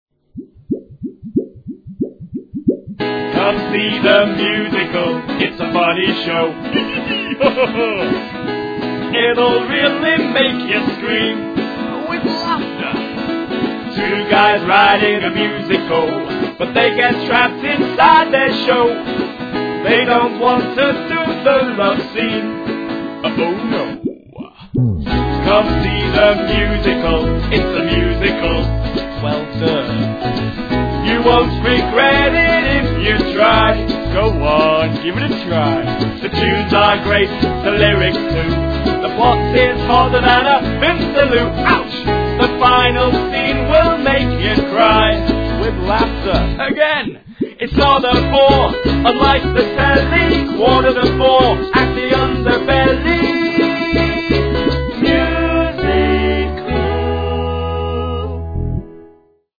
A comedy musical
jingle